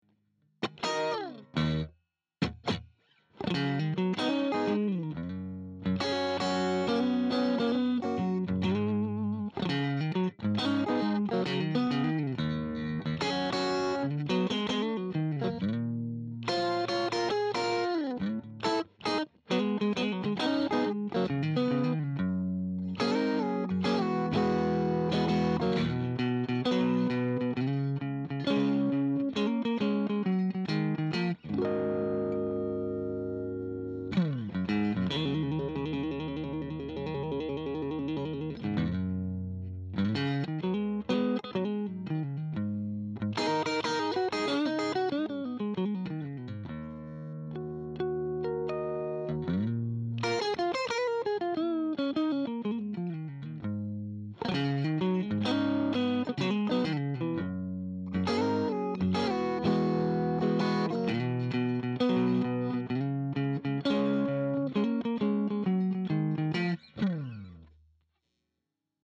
very good, very good tone and playing
I really like your use of octaves.